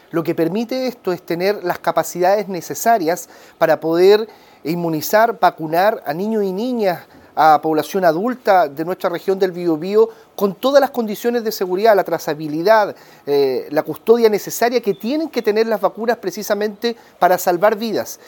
Por su parte, el delegado Presidencial, Eduardo Pacheco, destacó la importancia de los centros de almacenamientos de la región.